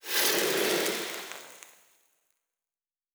Blacksmith 07.wav